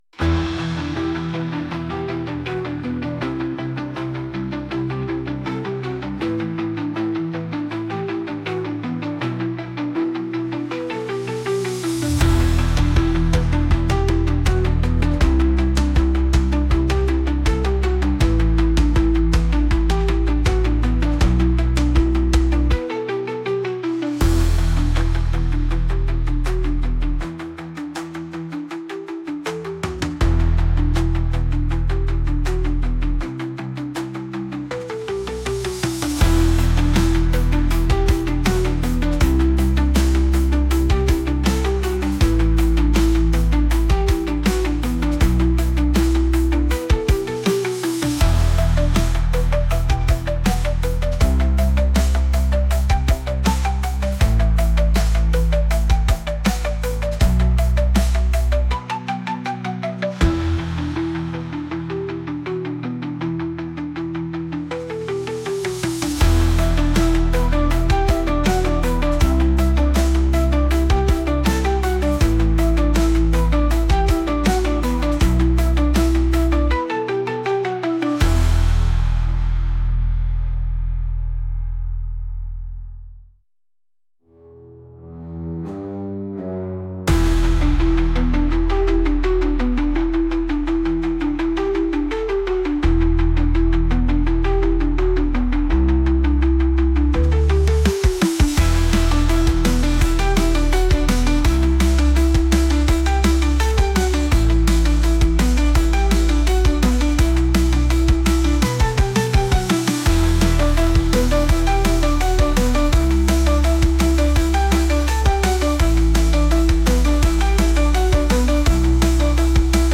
Инструменталка